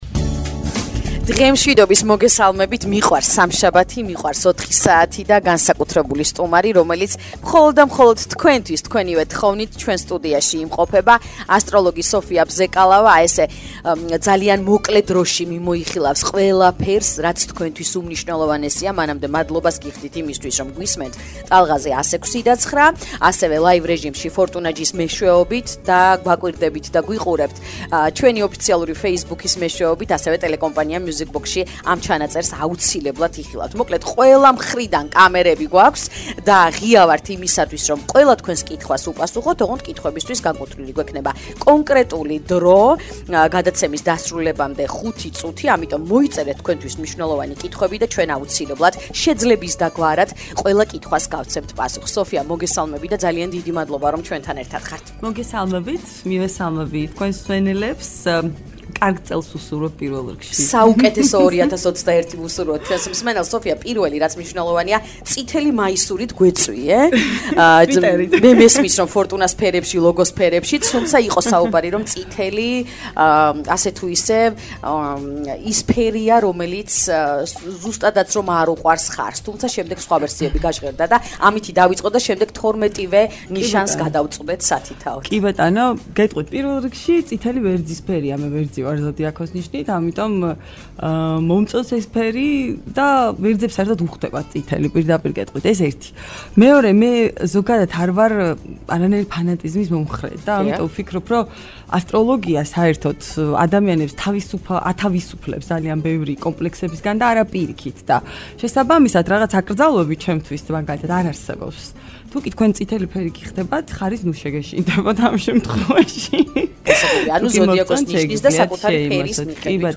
გადაცემის ლაივი LIVE